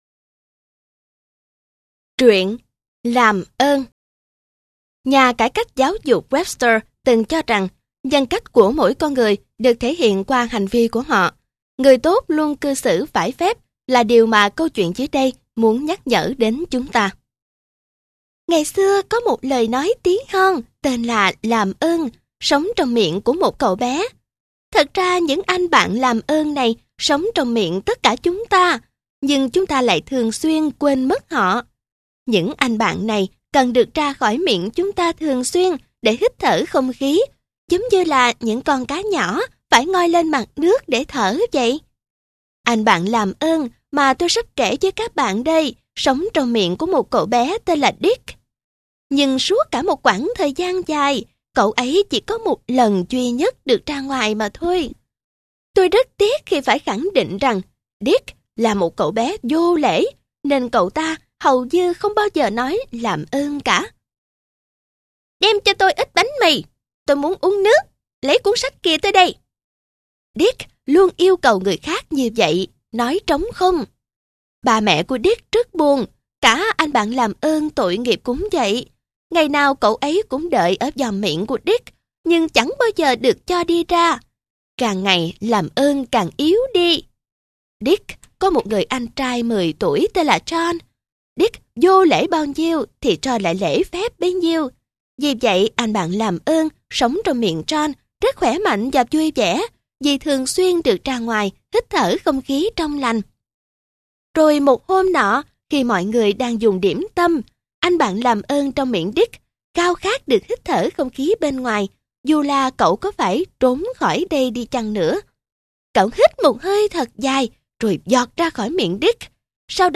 * Thể loại: Sách nói